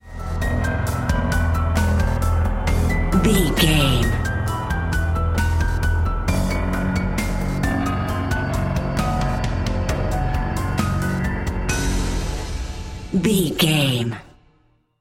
Thriller
Aeolian/Minor
dark
eerie
ominous
suspense
drum machine
piano
percussion
synthesiser